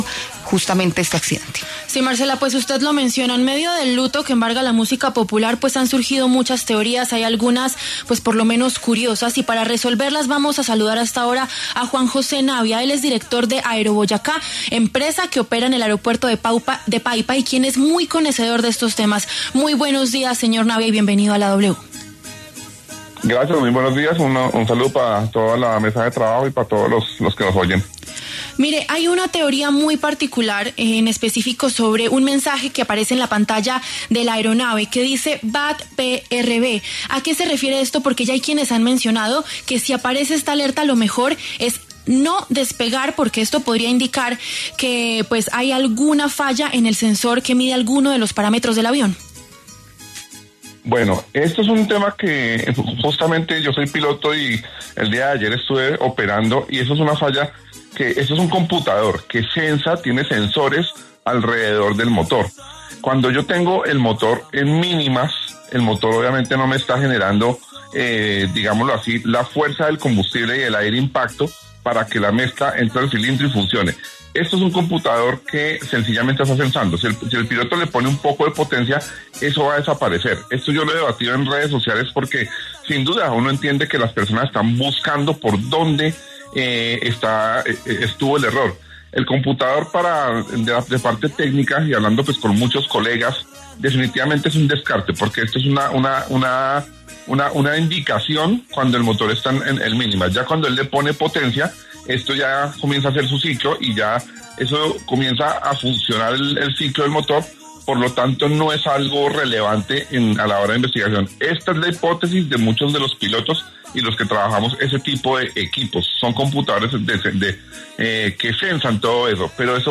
Juan José Navia, director general del Servicio Aéreo de Boyacá (AeroBoyacá), expuso en los micrófonos de La W irregularidades que se están presentando en el Aeropuerto de Paipa y pudieron influir en el accidente aéreo donde murieron Yeison Jiménez y cinco personas más.